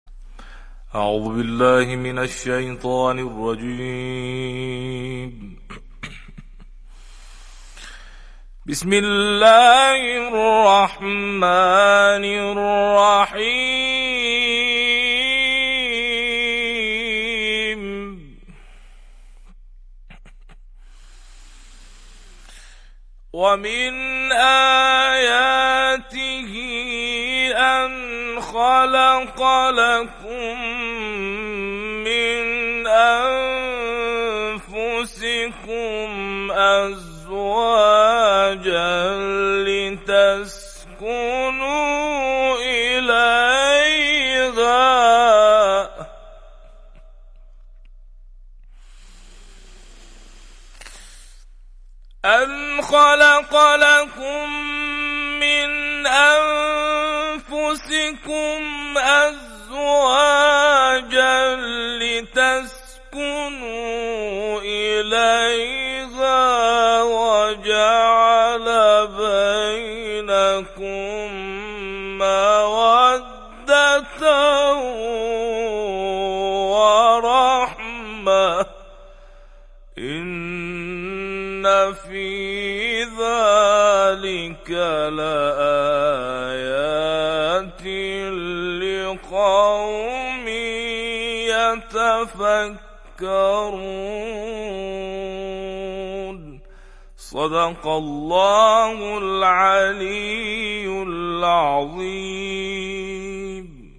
تلاوت آیه‌ای از قرآن که باعث حل مشکلی شد + صوت
قاری و مدرس قرآن کریم با تلاوت آیه 21 سوره روم گفت: وقتی برای حل مشکل یکی از خانواده‌ها مراجعه کرده بودیم، با تلاوت و به برکت این آیه باعث شد مشکل حل و زندگی زوجی حفظ شود.